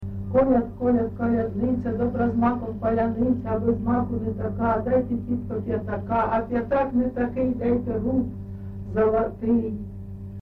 ЖанрКолядки
Місце записум. Костянтинівка, Краматорський район, Донецька обл., Україна, Слобожанщина